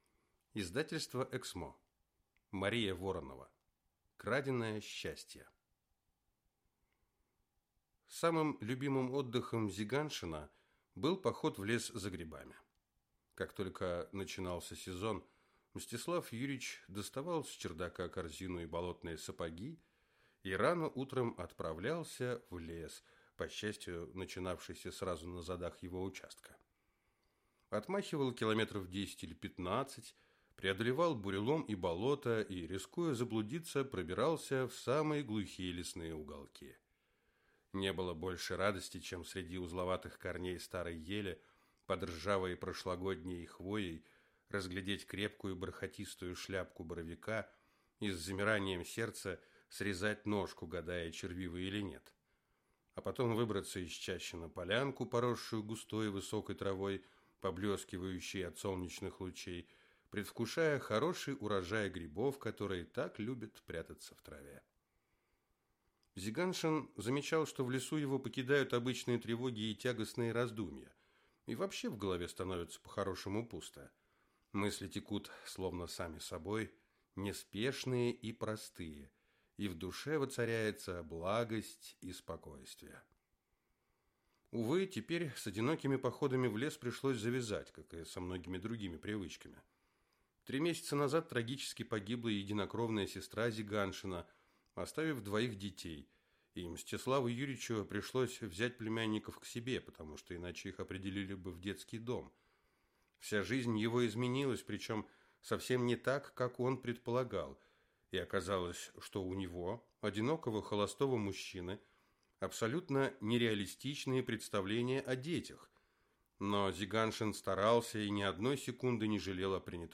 Аудиокнига Краденое счастье | Библиотека аудиокниг